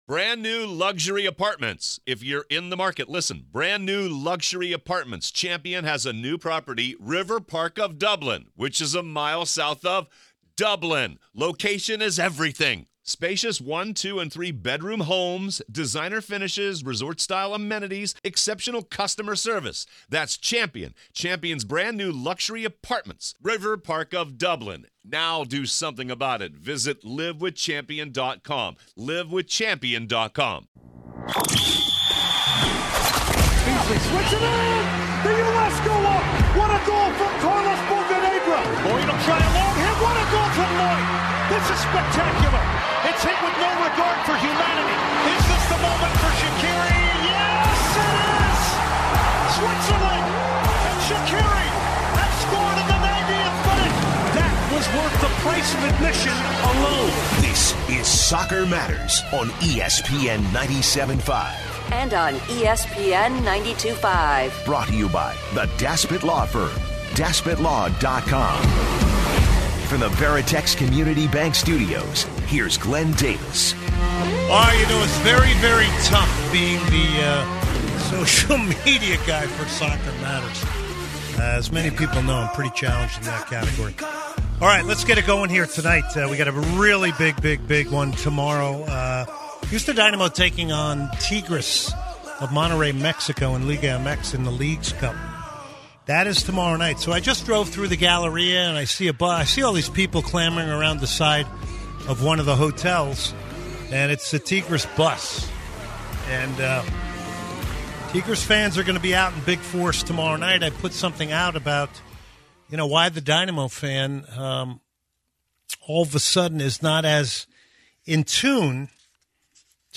PLUS Becky Sauerbrunn USWNT legend joined the show!